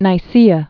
(nī-sēə)